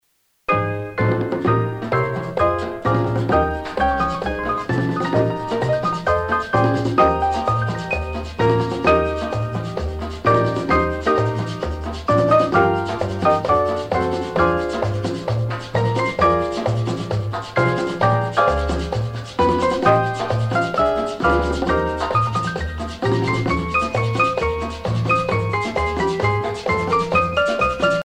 danse : merengue
Pièce musicale éditée